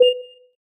pause-back-click.mp3